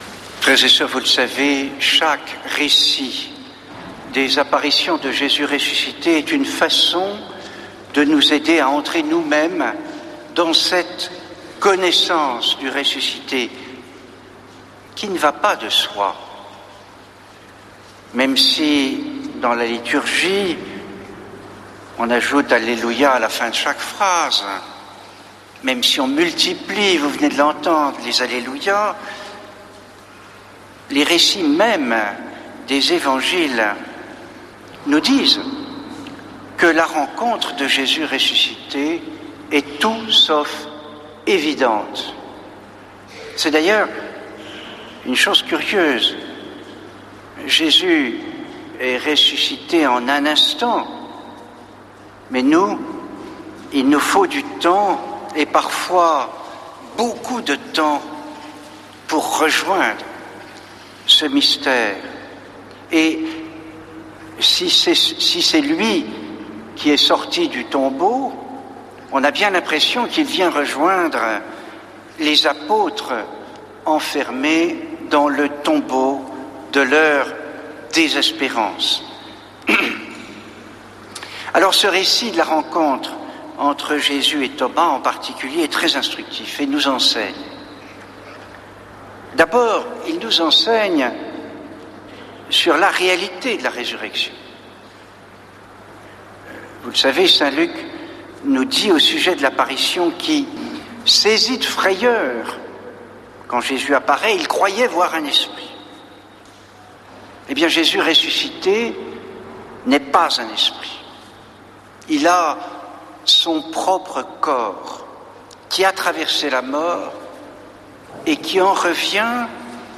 Messe depuis le couvent des Dominicains de Toulouse
homelie